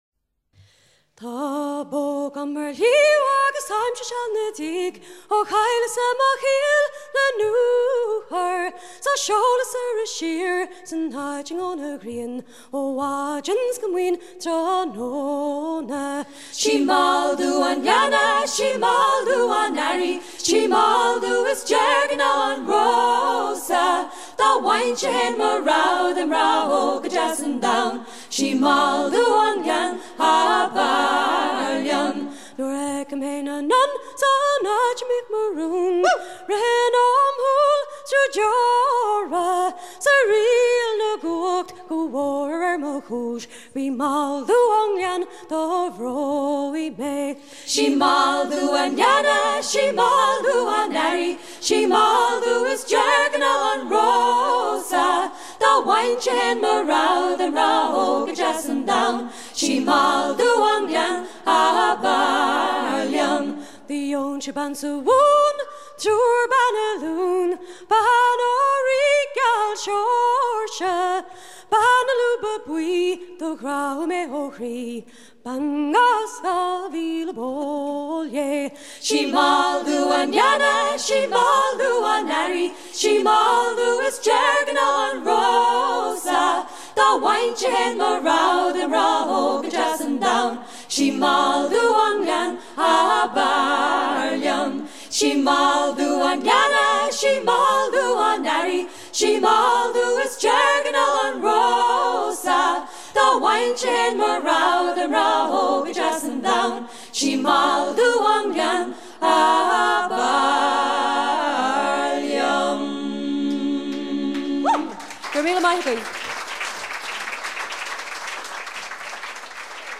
chanson gaélique irlandaise décrit le désespoir d'un jeune homme abandonné par sa bien-aimée
Pièce musicale éditée